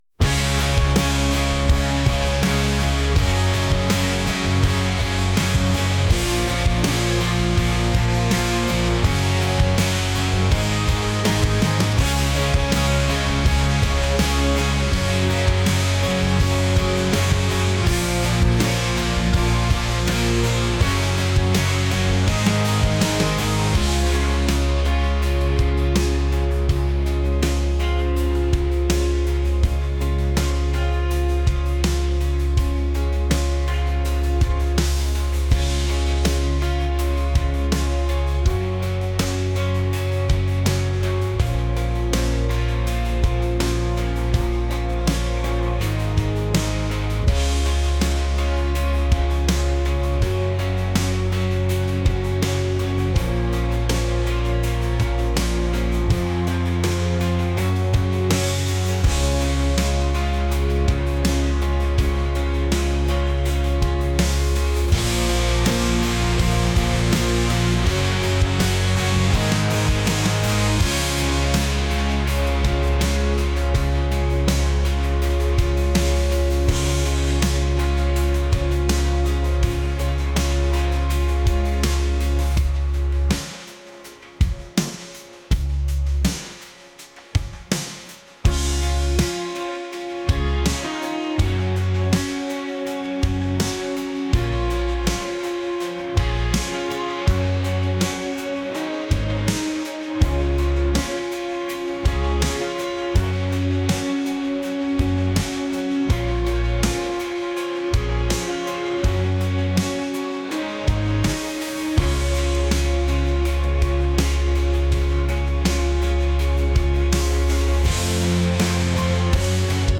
rock | indie | alternative